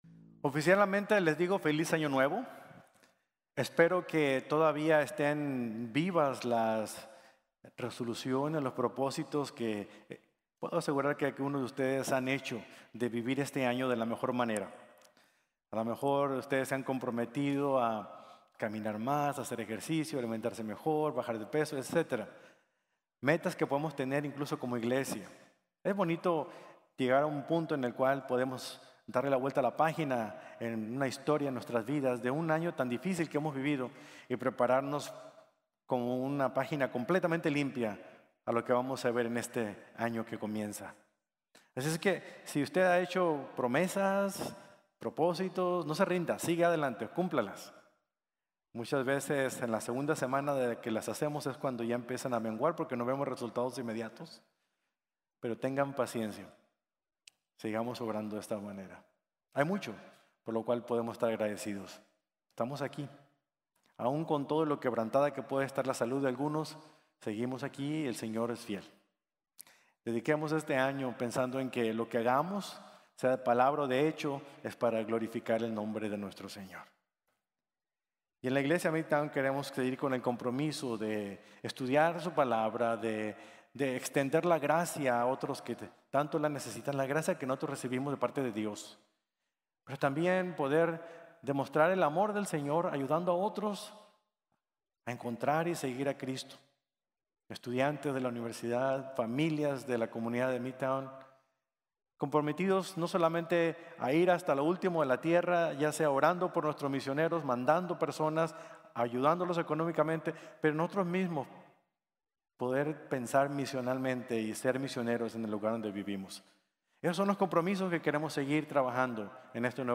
Un Cuerpo Consagrado | Sermon | Grace Bible Church